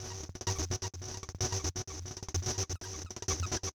crunchy monotron 2 F#.wav
Sound designed using multiple effects processors and gaters, applied on modular gear (Korg)